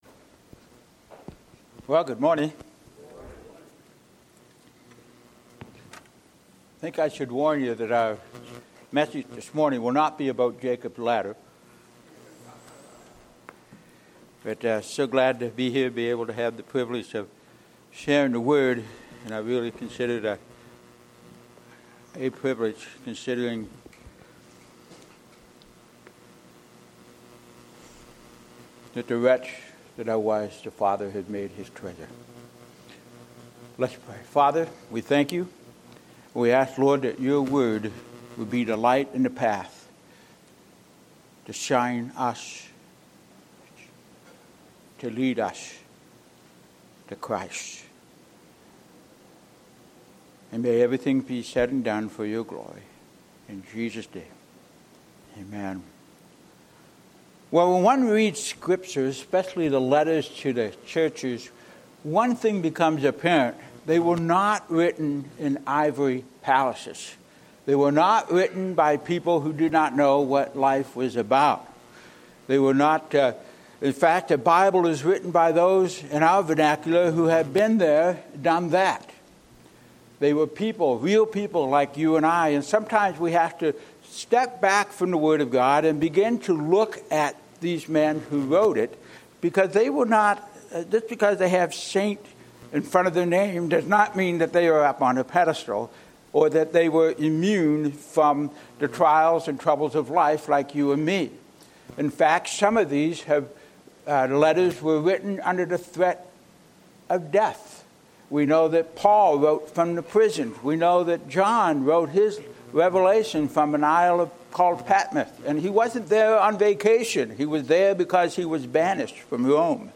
Sermons | Evangelical Church of Fairport